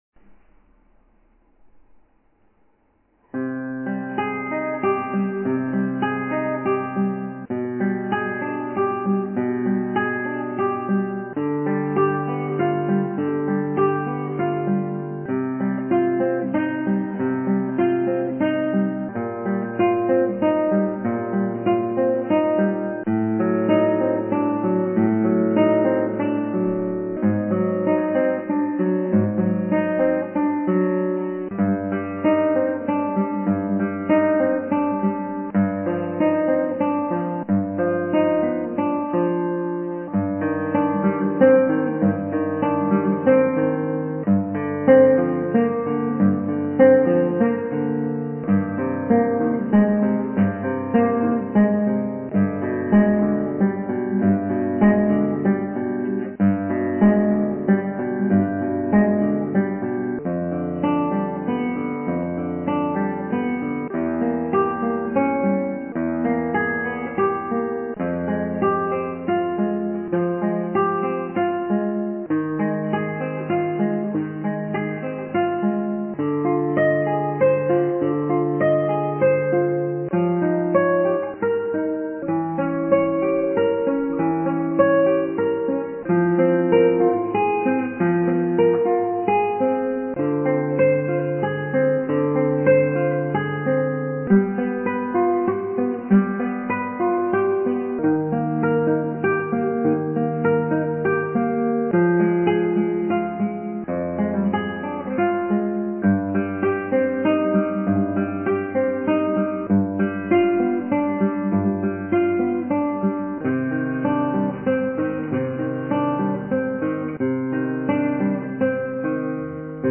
アルカンヘルで